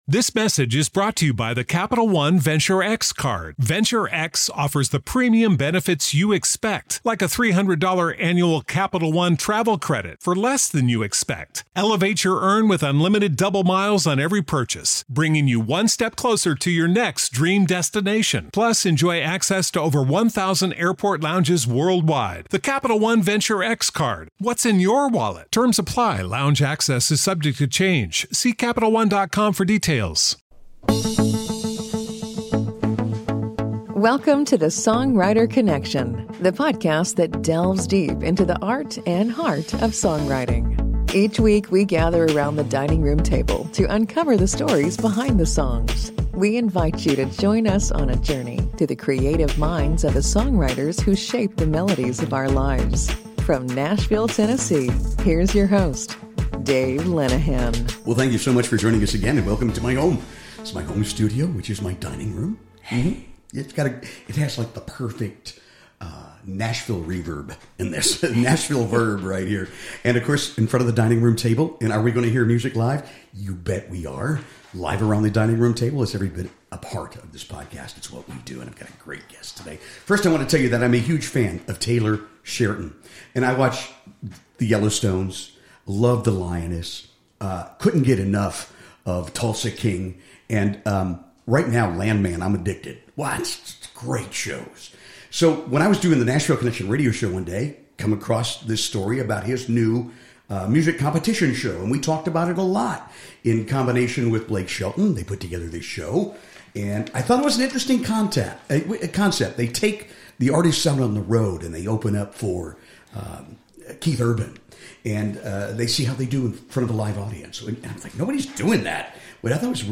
Songwriters Connection interviews and music of Nashville songwriters